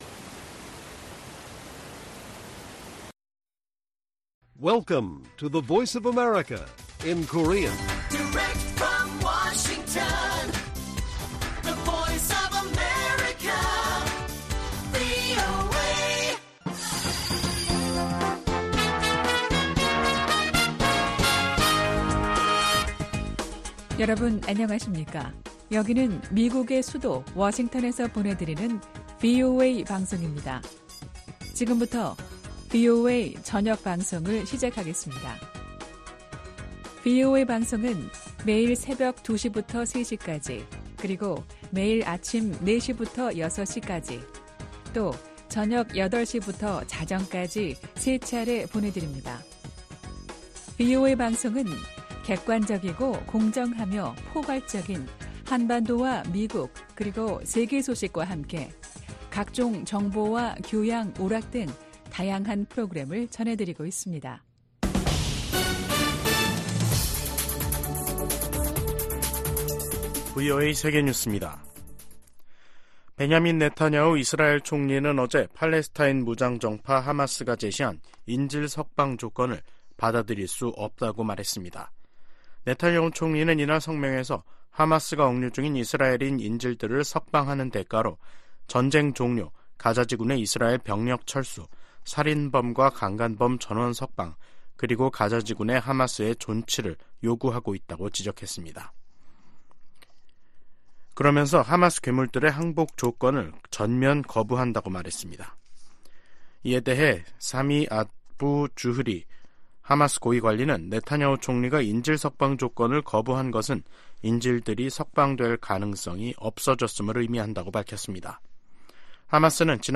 VOA 한국어 간판 뉴스 프로그램 '뉴스 투데이', 2024년 1월 22일 1부 방송입니다. 미 국무부가 북한의 수중 핵무기 시험 주장에 도발을 중단하고 대화에 나서라고 촉구했습니다. 백악관은 수중 핵무기 시험 주장과 관련해 북한이 첨단 군사 능력을 계속 추구하고 있음을 보여준다고 지적했습니다. 과거 미국의 대북 협상을 주도했던 인사들이 잇달아 김정은 북한 국무위원장의 최근 전쟁 언급이 빈말이 아니라고 진단하면서 파장을 일으키고 있습니다.